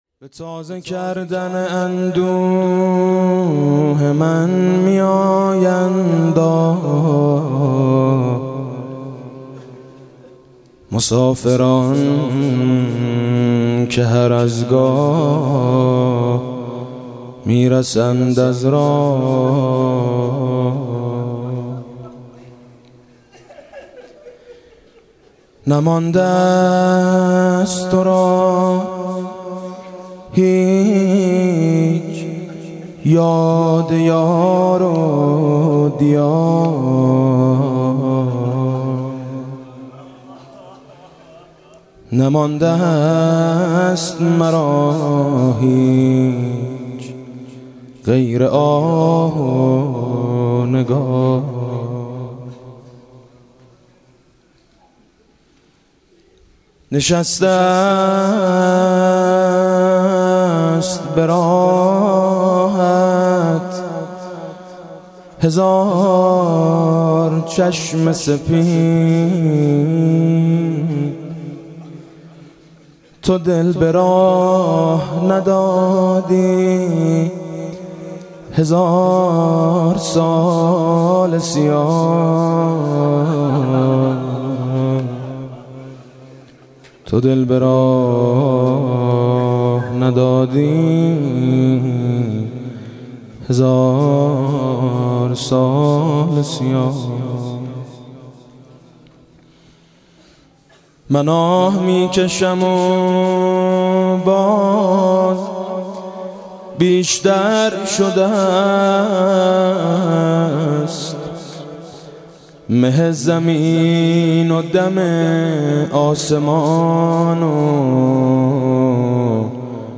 مداحی میثم مطیعی با شعری از محمدمهدی سیار در محرم 91